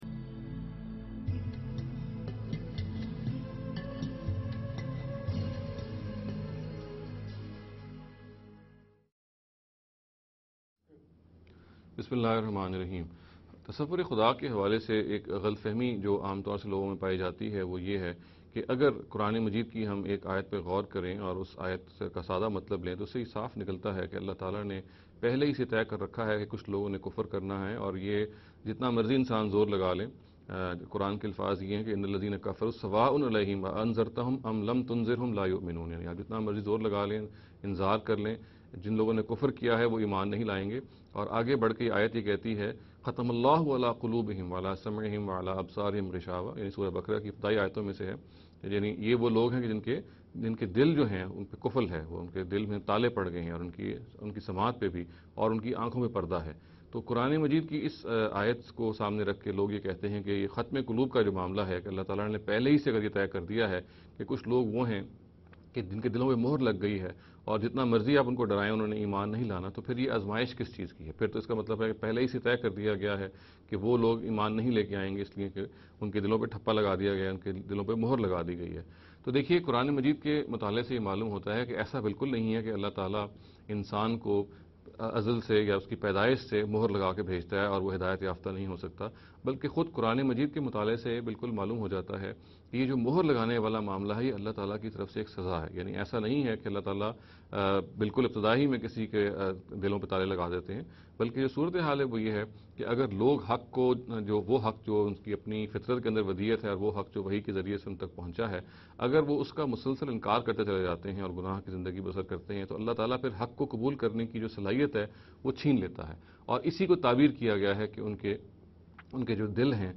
This lecture series will deal with some misconception regarding the Concept of God.